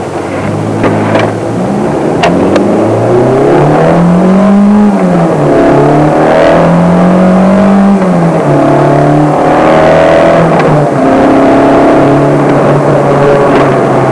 It's a sound clip I recorded just by placing a small tape recorder on the passenger's seat. The clip is pretty short - it starts with me at rest at the intersection of French Cross Road and Route 9, and then pulling out onto Route 9 and winding through the first 4 gears. It was many years ago, but as I recall, the reason I did this was to document how she sounded after a few modifications.
I have to admit, she sounds even better than I remember.